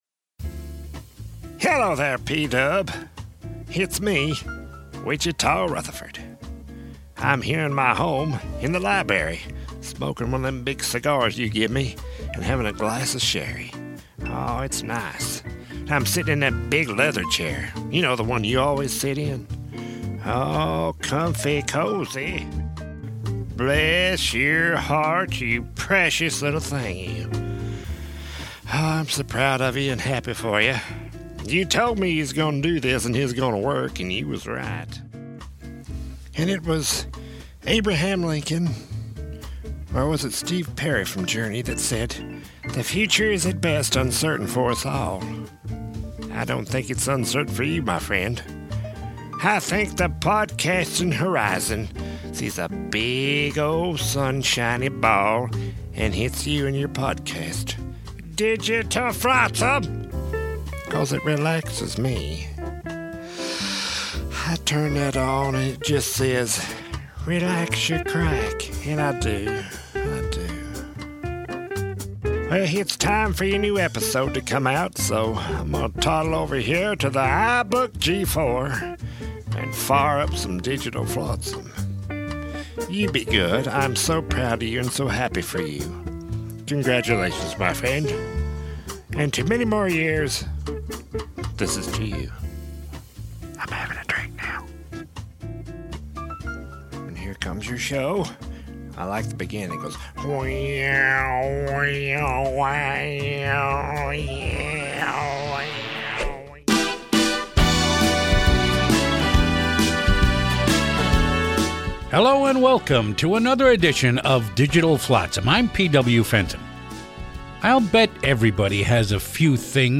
It appeared for several years nationally on Sirius Satellite Radio. We are proud to offer these great spoken word pieces again.